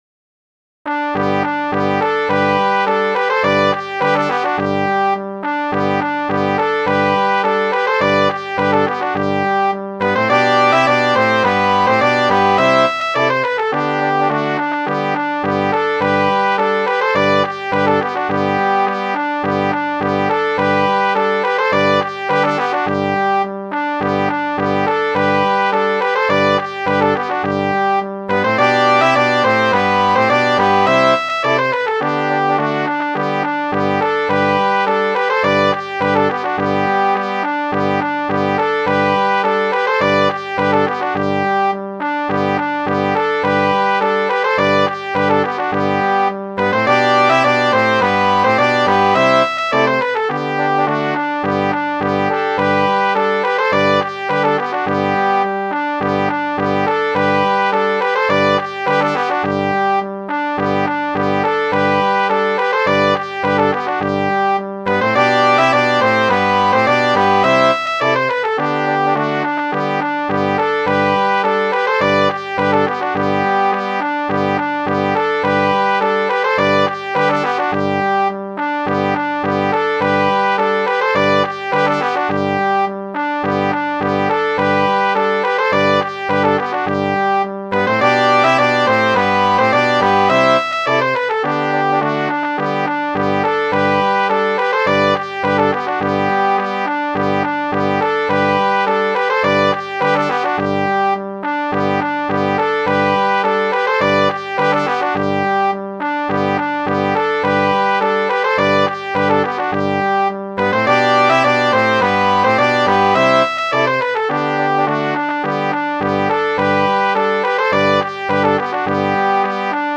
Midi File, Lyrics and Information to Free America